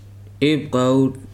[éèjp káúl] n. piece of mustard